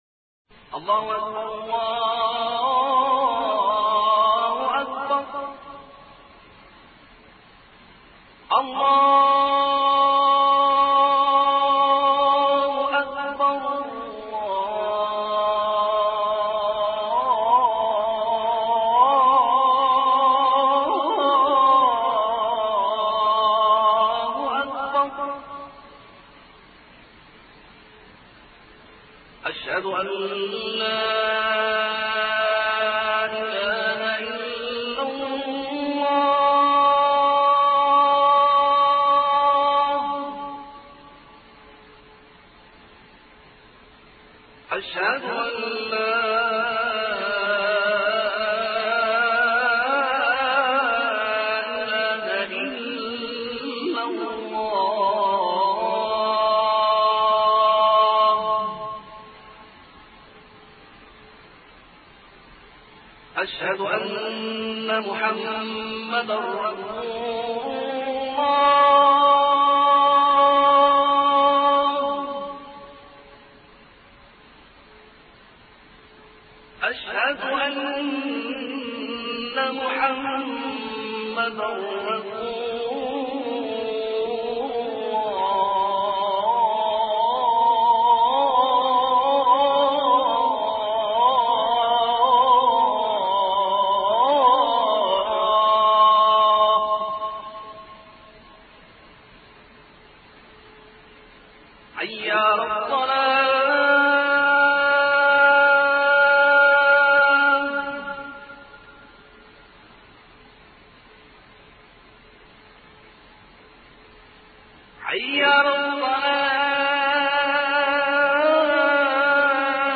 عنوان المادة أذان الفجر - مصــــر